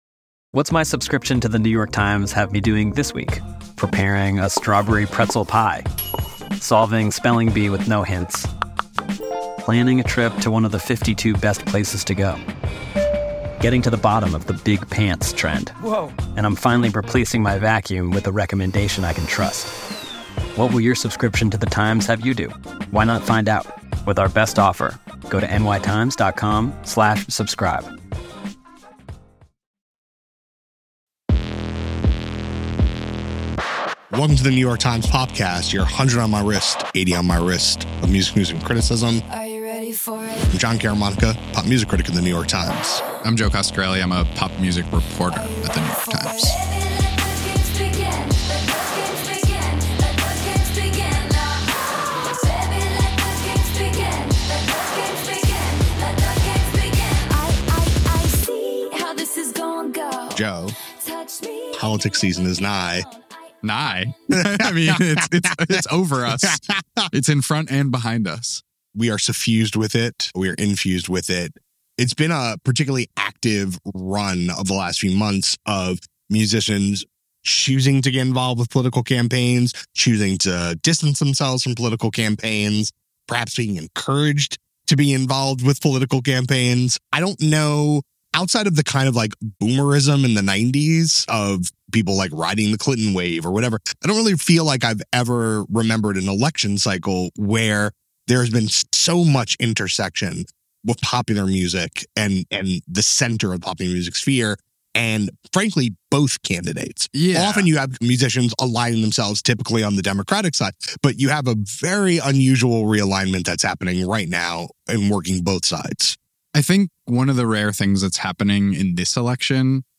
A discussion about the ways in which musicians and social media stars, both mainstream and more obscure, have figured into the current presidential campaign.